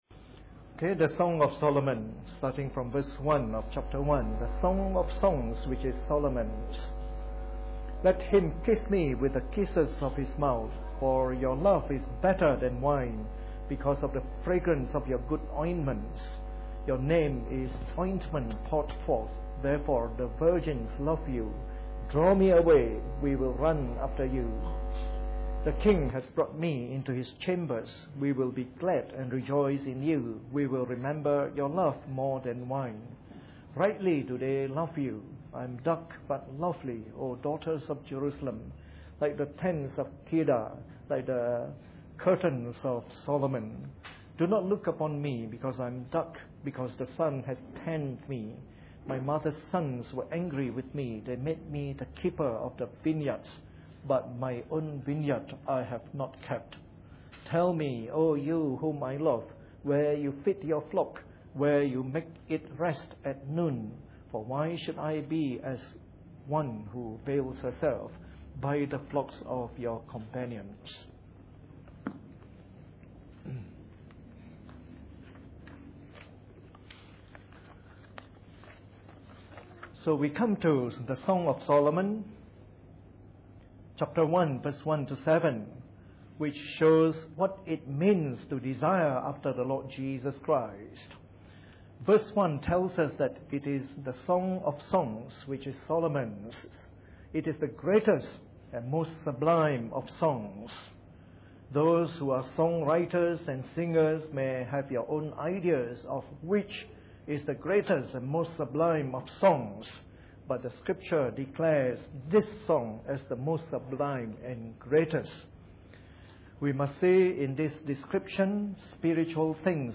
Preached on the 28th of September 2011 during the Bible Study from our new series on the Song of Solomon.